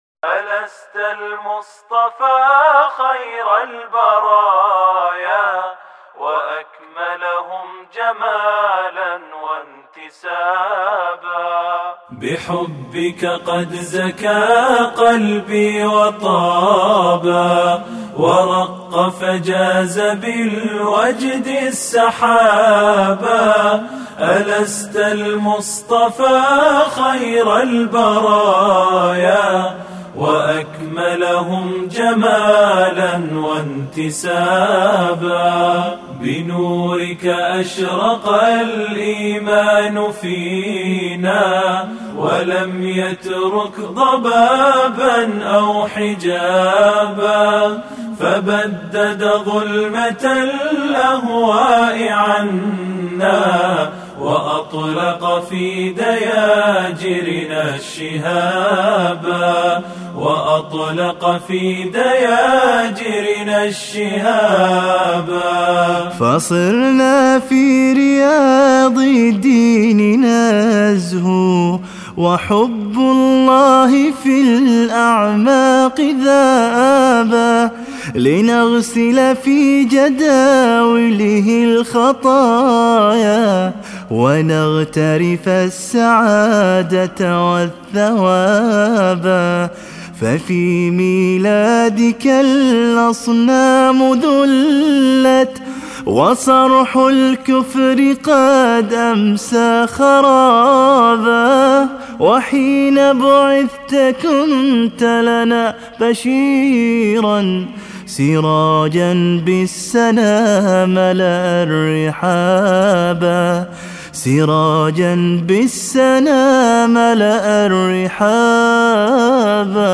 الاناشيد